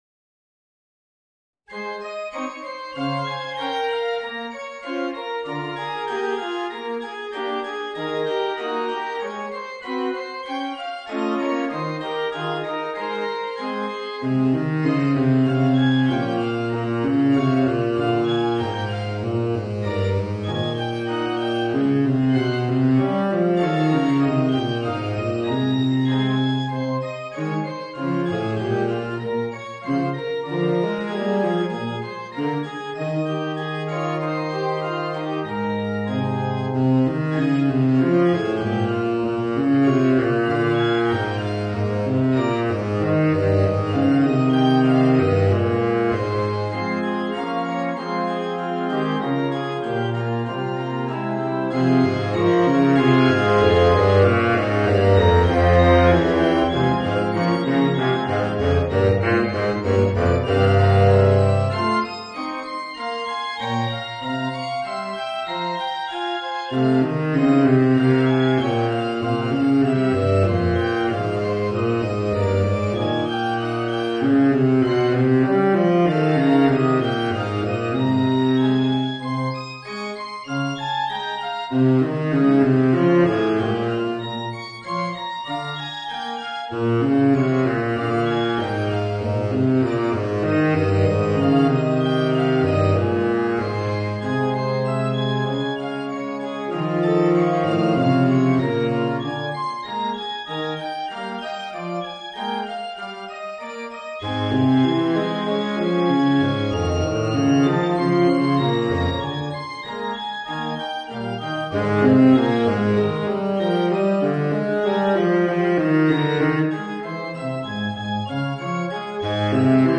Saxophone baryton & piano